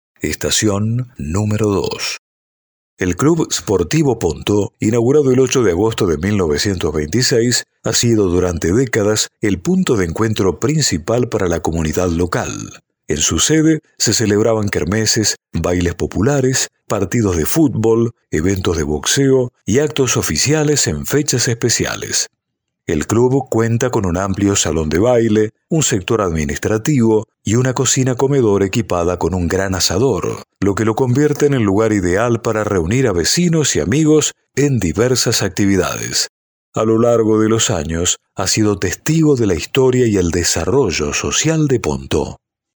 AUDIO-GUIA-PONTEAU-ESTACION-DOS.mp3